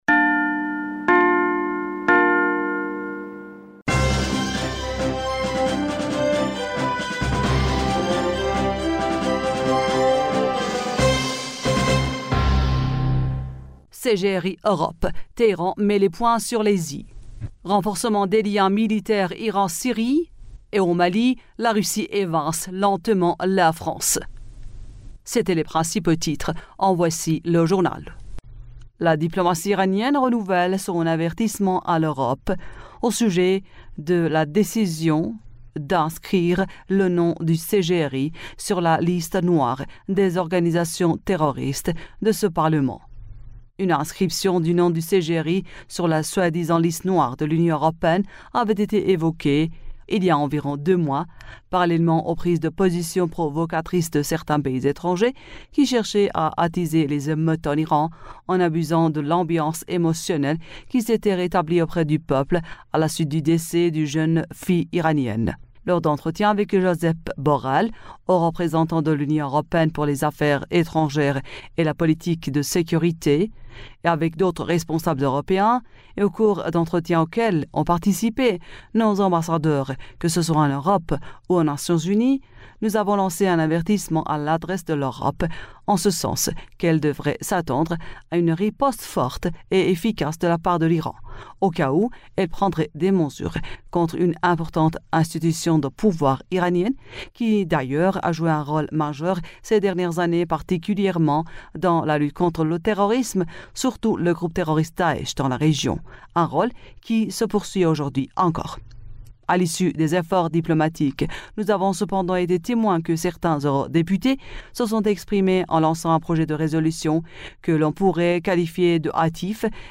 Bulletin d'information du 24 Janvier